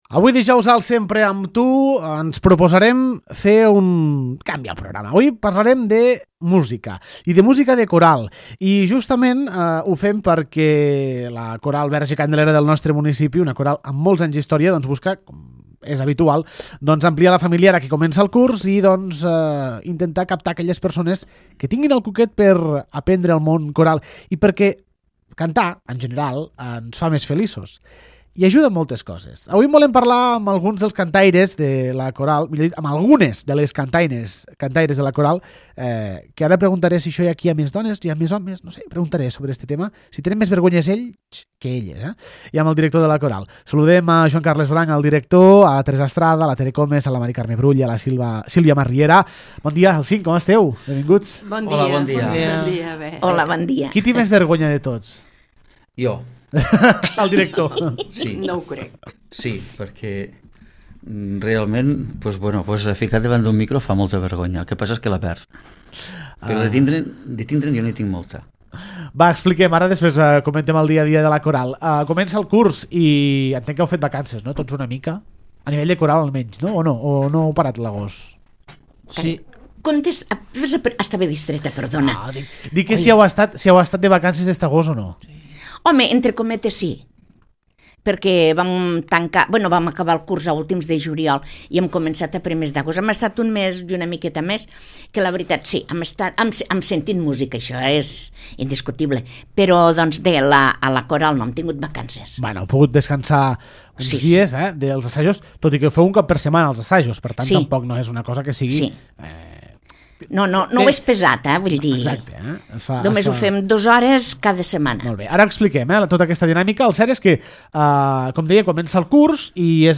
La tertúlia: la Coral Verge Candelera